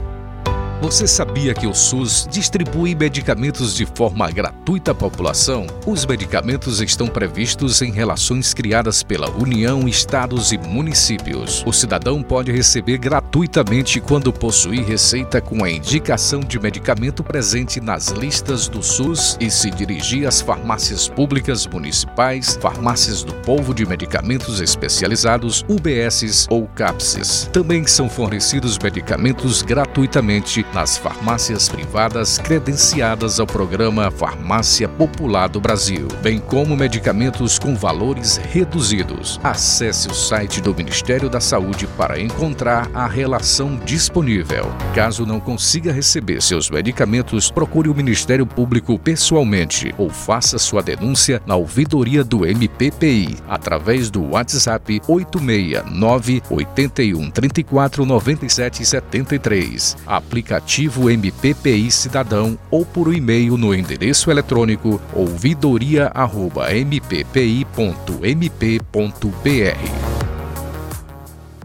Spot-CAMPANHA-ASSISTENCIA-FARMACEUTICA.mp3